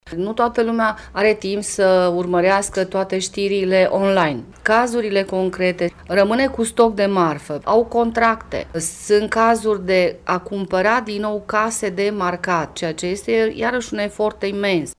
Consultantul în înființări firme